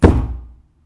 mech_jump.ogg